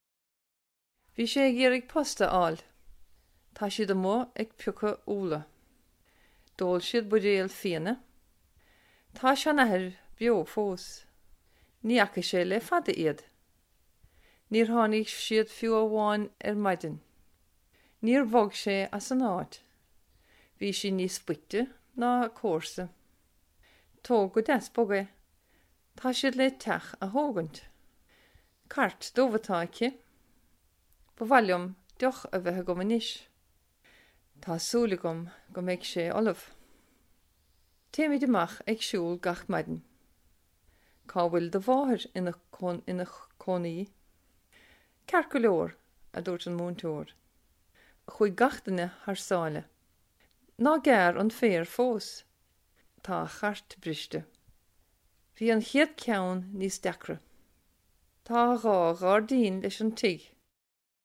Sample sound files for Modern Irish
Each sample here is from the beginning of a recording in which the particular speaker read out a set of sentences containing tokens of the lexical sets devised for the phonology of modern Irish.
Ceann_Tra_(F_45)_S.wav